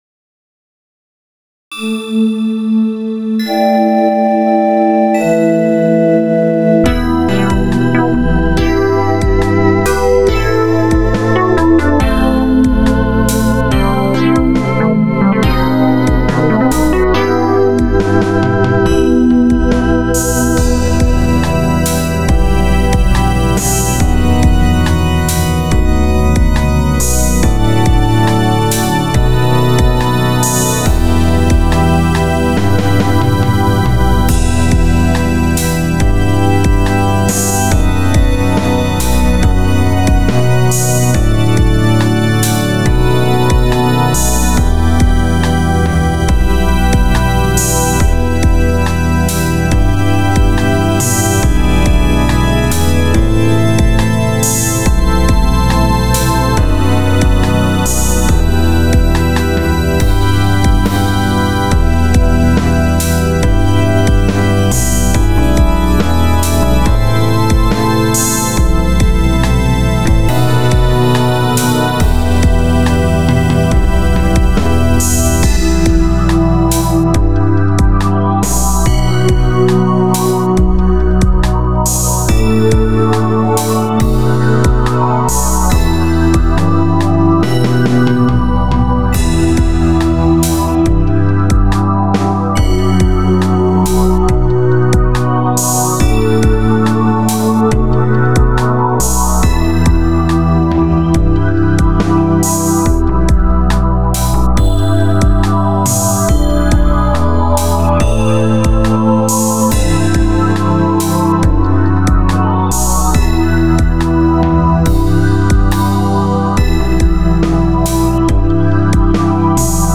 Стиль: Популярна музика